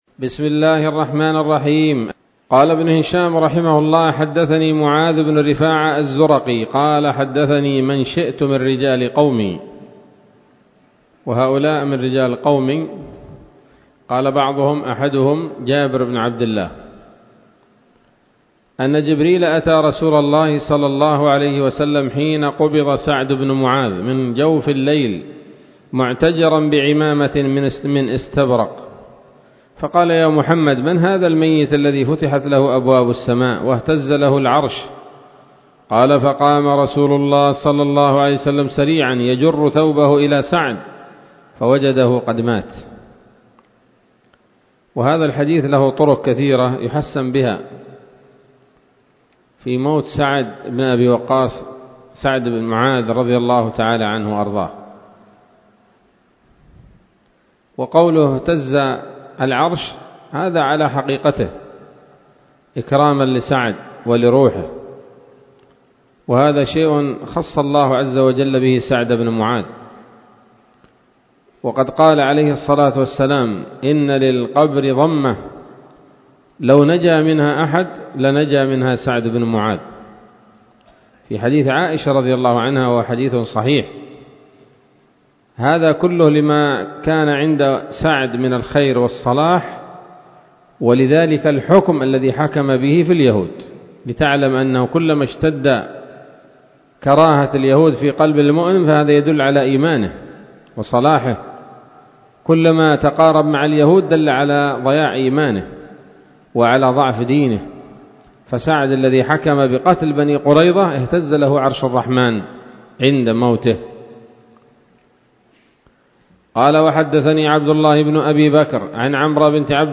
الدرس الثاني عشر بعد المائتين من التعليق على كتاب السيرة النبوية لابن هشام